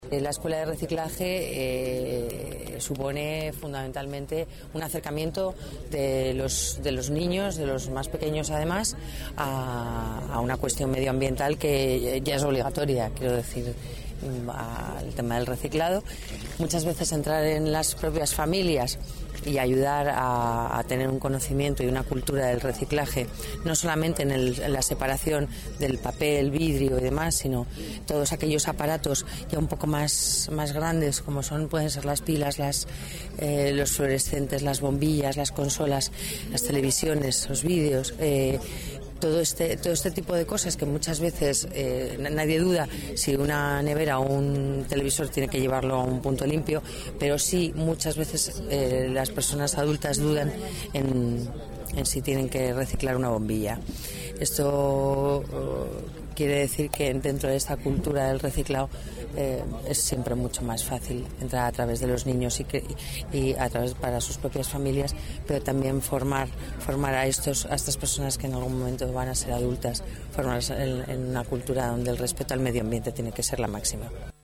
Nueva ventana:Declaraciones de la delegada de Familia y Servicios Sociales, Lola Navarro, sobre la Escuela de Reciclaje